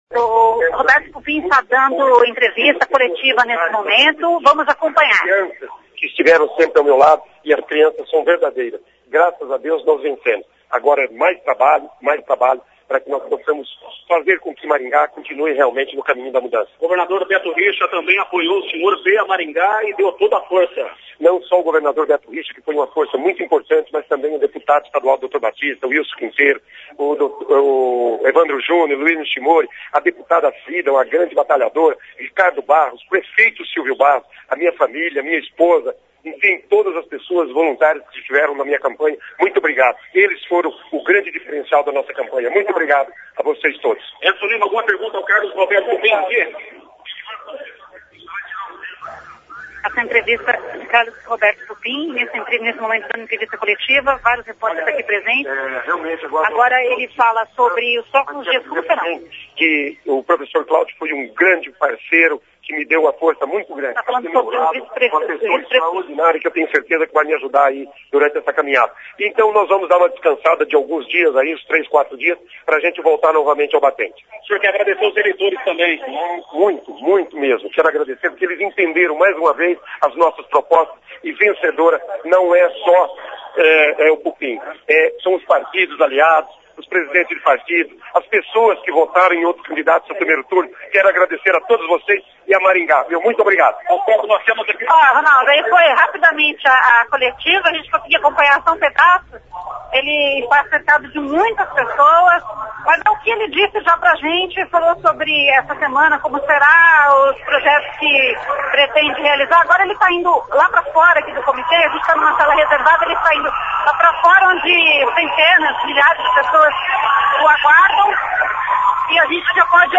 Roberto Pupin agradece votos recebidos e fala como será seu mandato em entrevista coletiva